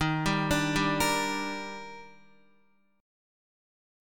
EbM7 Chord